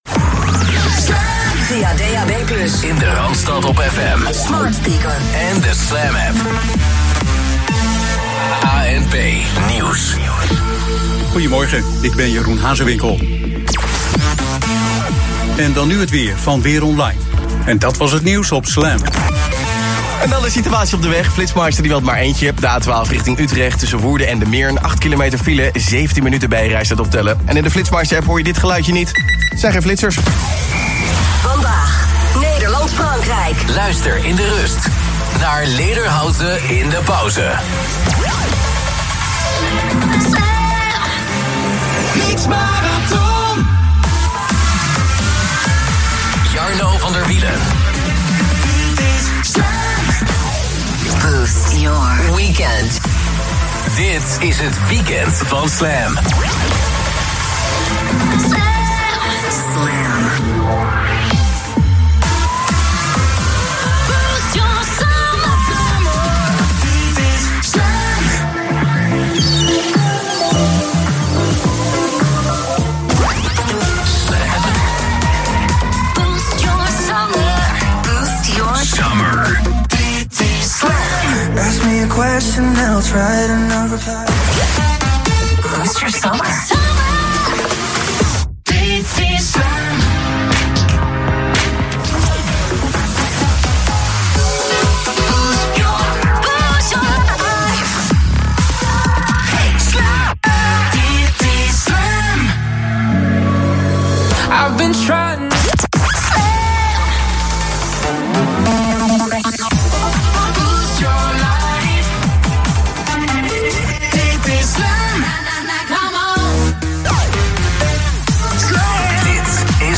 een deel van de jingles ingezongen